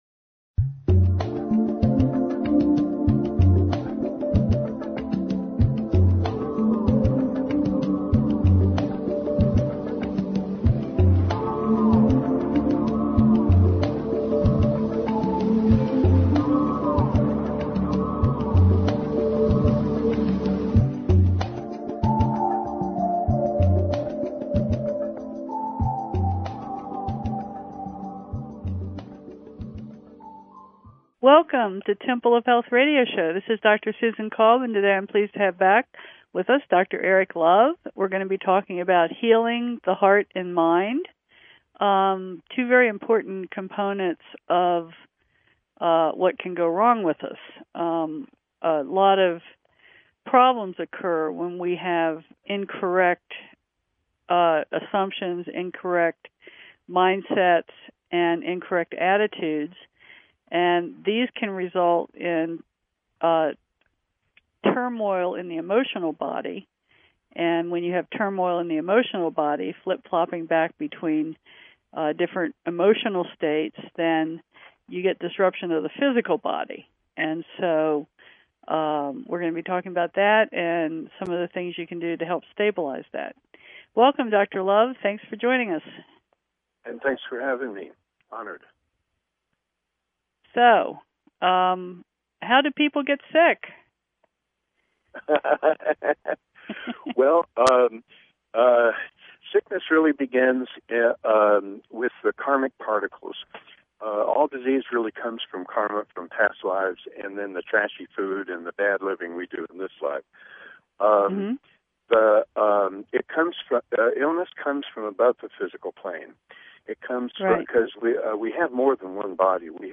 Talk Show Episode, Audio Podcast, Temple_of_Health_Radio_Show and Courtesy of BBS Radio on , show guests , about , categorized as